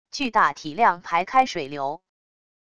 巨大体量排开水流wav音频